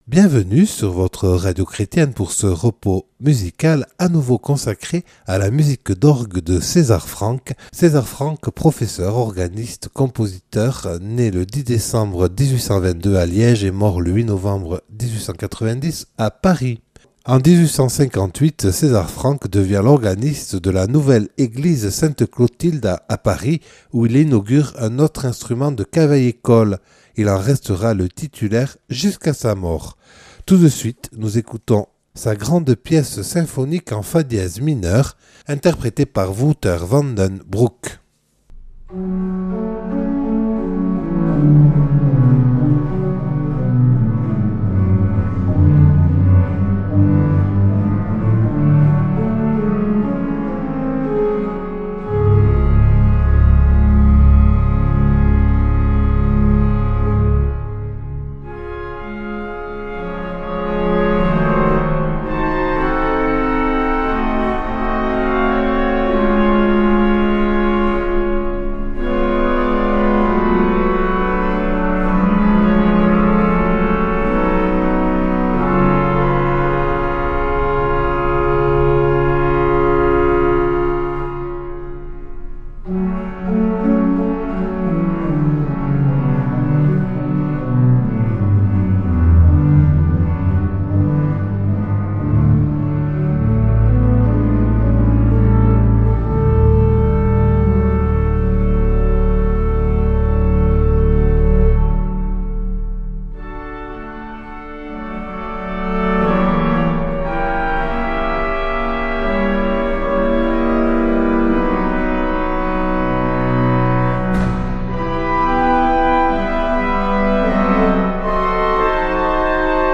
Cesar Franck Orgue 02